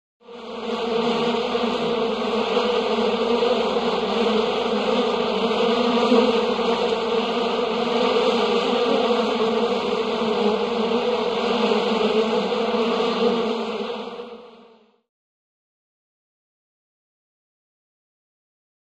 Bee Swarm, Intense Buzzing, Interior Large Room.